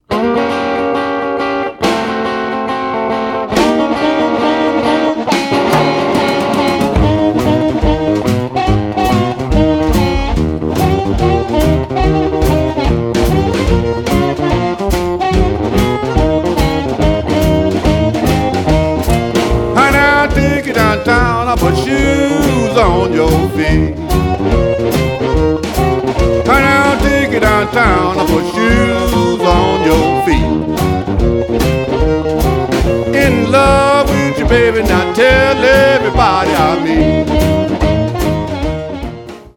traditional blues